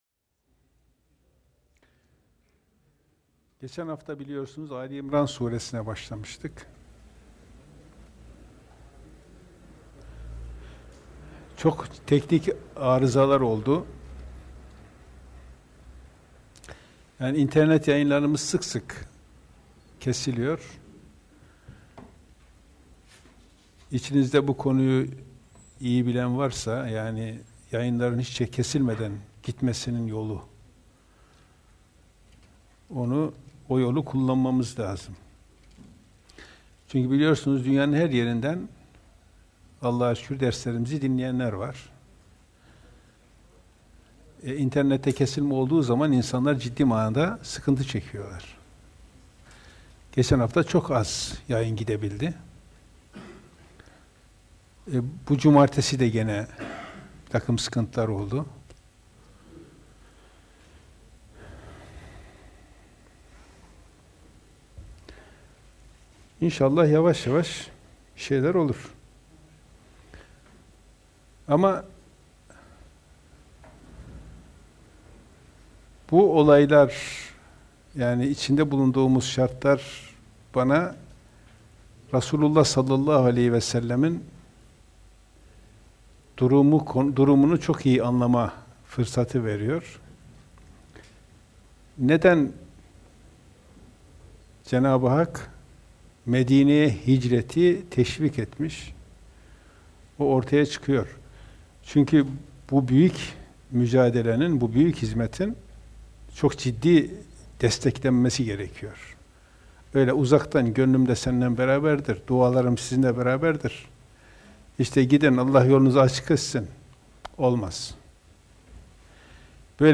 DERS: Kuran’ı ikinci sıraya koymak